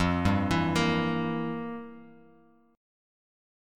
Listen to FM7sus2sus4 strummed